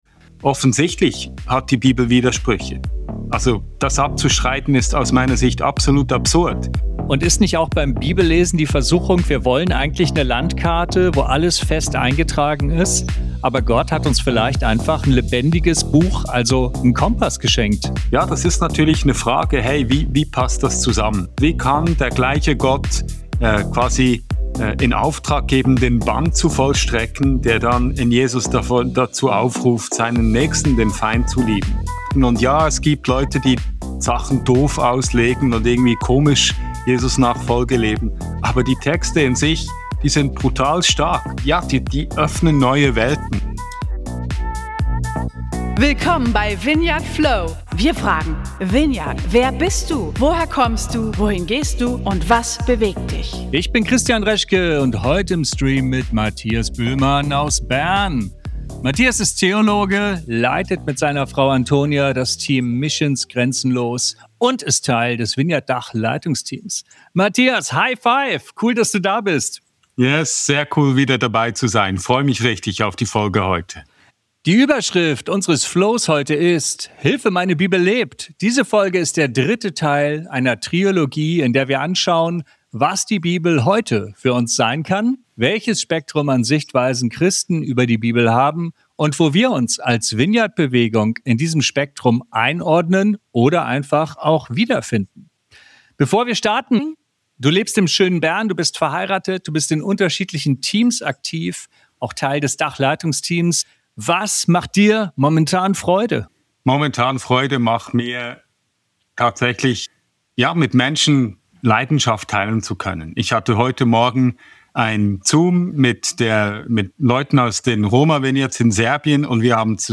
Sie diskutieren verschiedene Perspektiven auf die Bibel und ordnen Vineyard in diesem Spektrum ein.